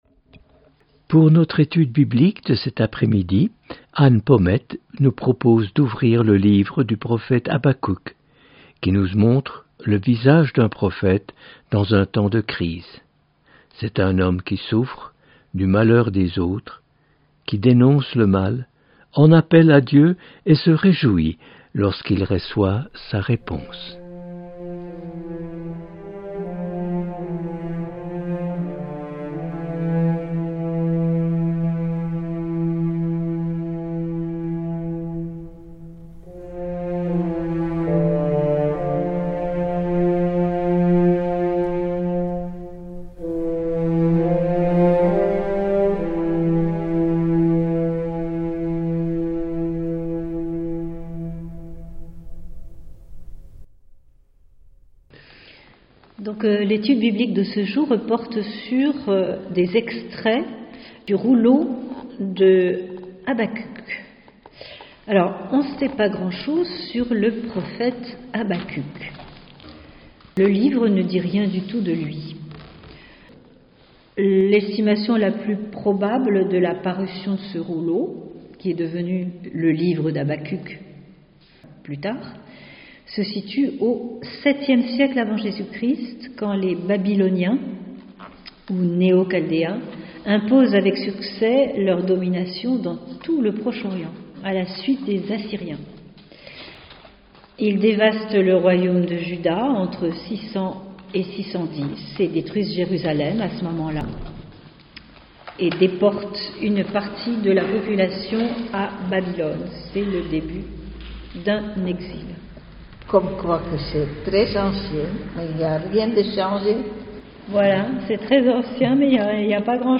Etude biblique